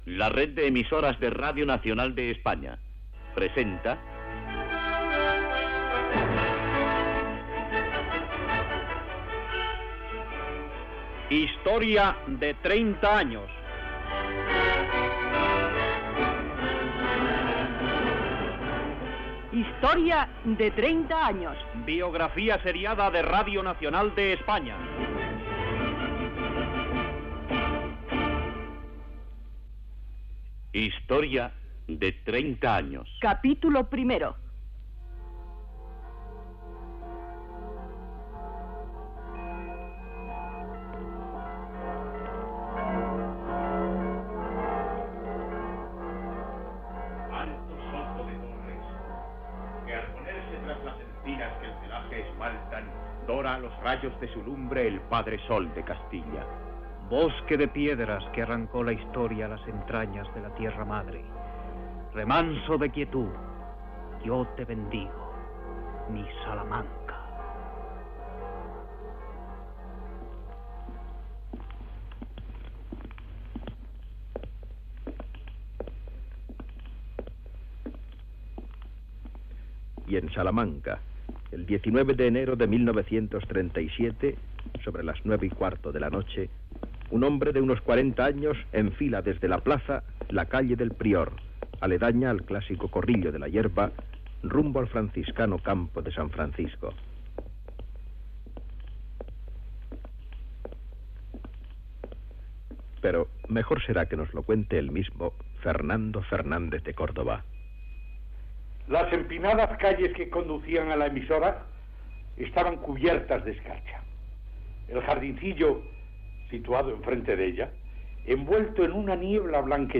Careta del programa. El naixement de RNE a Salamanca el 19 de gener de 1937.